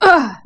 pain100_1.wav